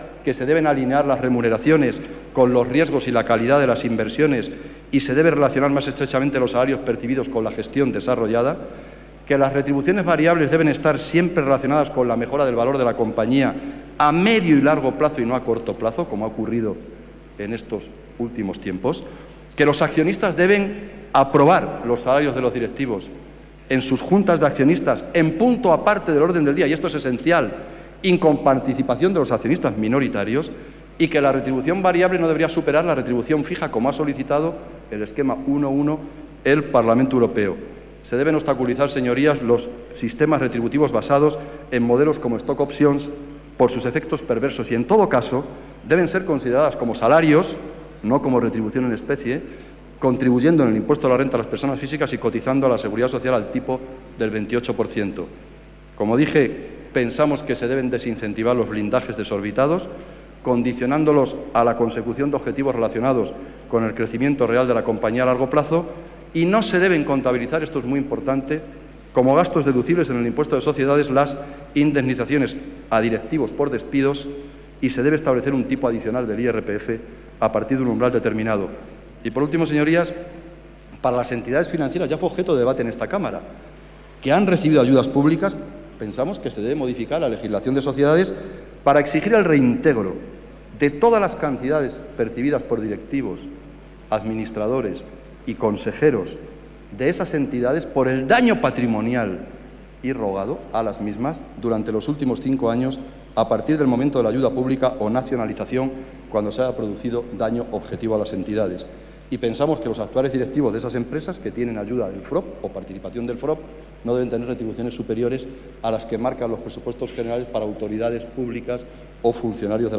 Jesús Caldera, Pleno del Congreso. Proposición no de ley para limitar los sueldos de los directivos de las grandes empresas y bancos 7/05/2013